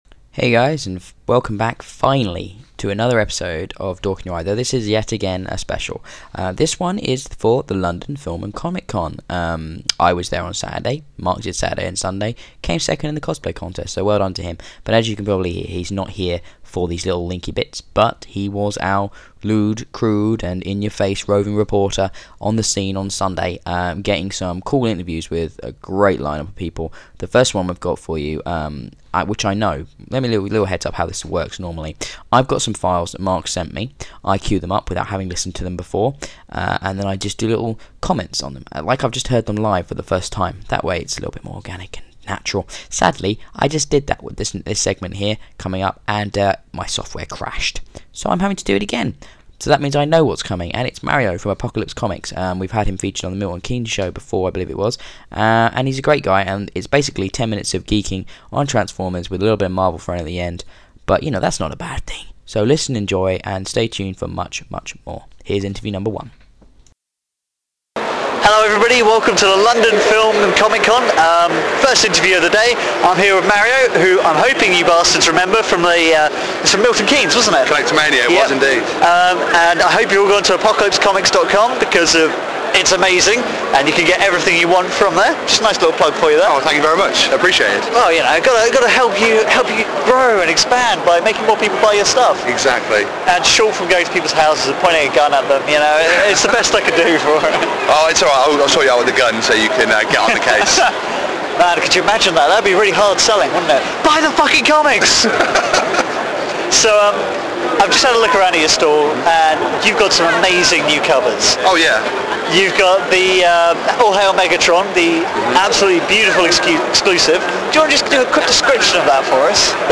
Latest Episode The London Film and Comic Con.mp3 More convention crazyness! 6 more interviews for your enjoyment. So here we are with our London Film and Comic Convention coverage.
As you will find out from listening to the show, in keeping with our special show tradition this episode is without music.
The London Film and Comic Con.mp3